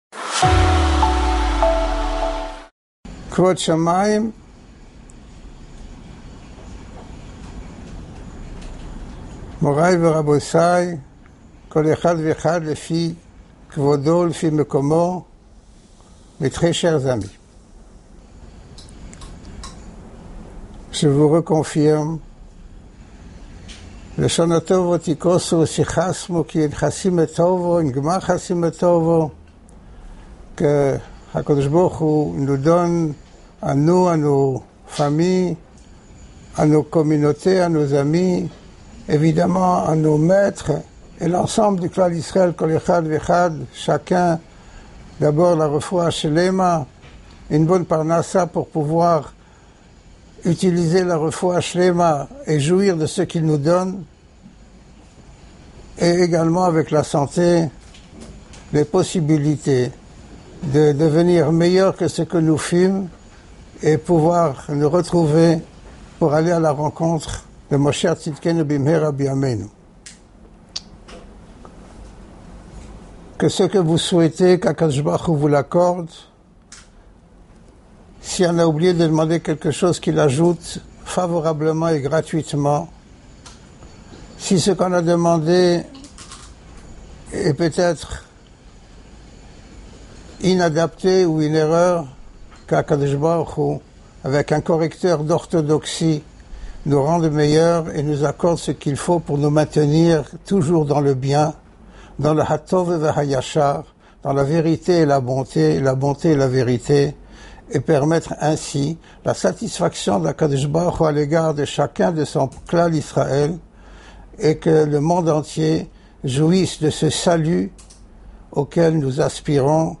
Dracha annuelle - L'énergie de Chabbath Chouva